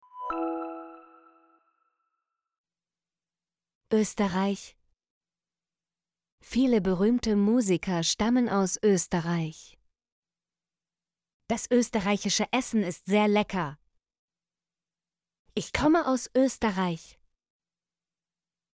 österreich - Example Sentences & Pronunciation, German Frequency List